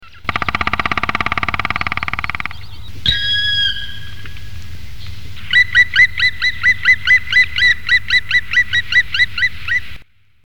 Pic noir
Dryocopus martius
pic-noir.mp3